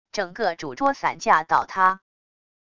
整个主桌散架倒塌wav下载